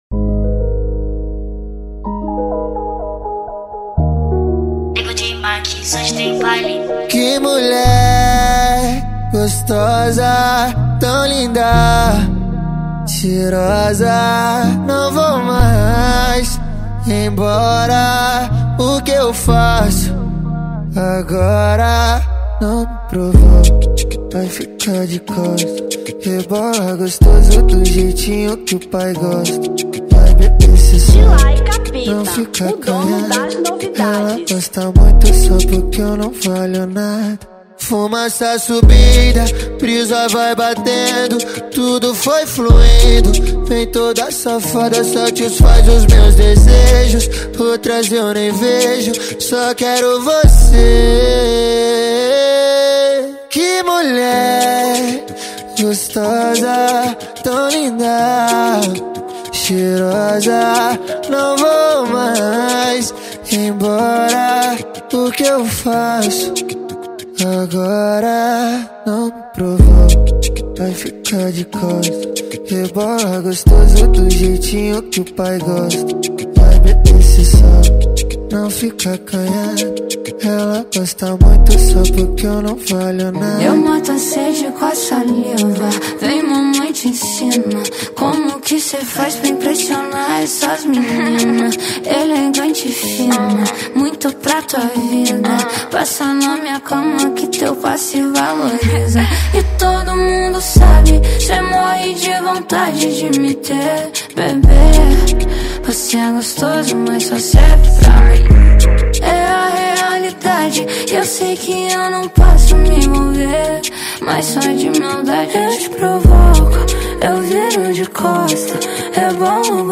Afro Funk 2025